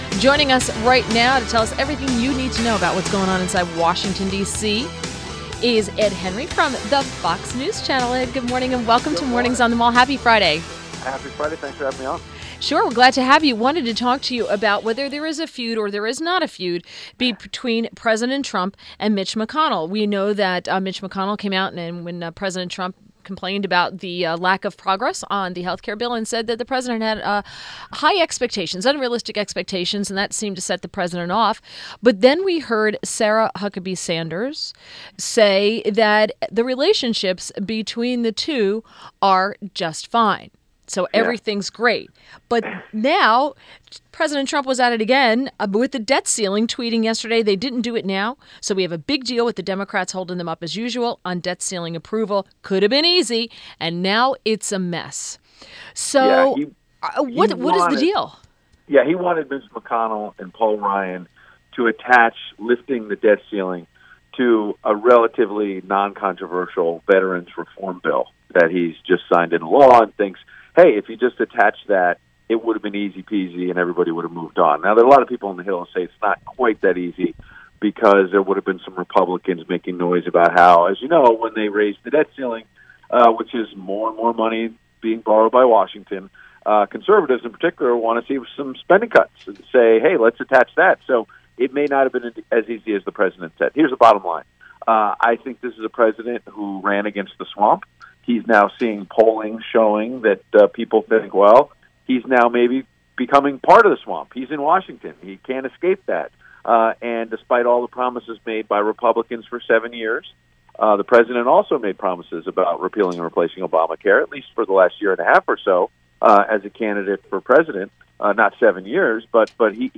WMAL Interview - ED HENRY - 08.25.17